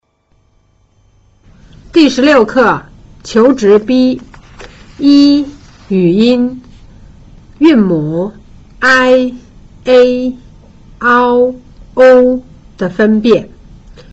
一、語音
複韻母的發音要注意唇形和舌位的逐漸變化。